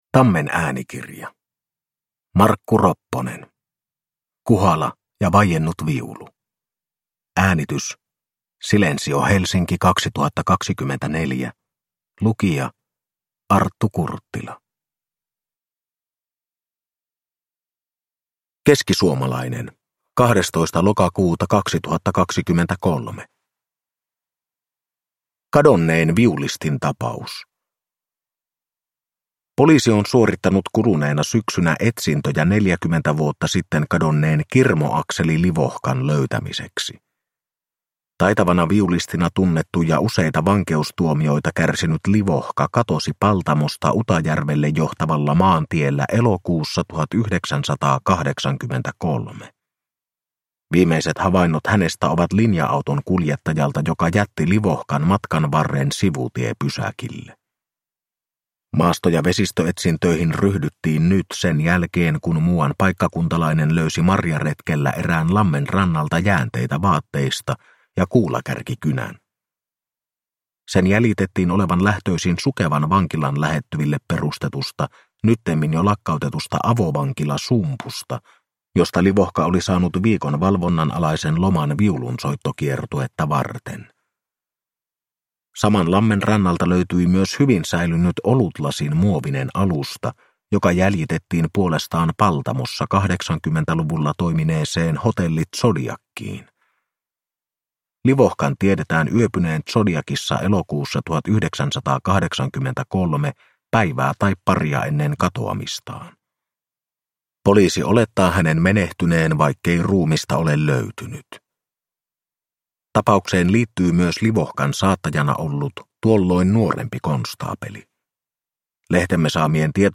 Kuhala ja vaiennut viulu – Ljudbok